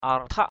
/a:r-tʰaʔ/ (cv.) arsak aRsK [A,21] /a:r-saʔ/ (cv.) arisak ar{sK [A,20] /a-ri-saʔ/ (d.) không khí, không gian, vô cùng = éther, espace infini. air; infinite space. matâh arthak mtH aREK giữa...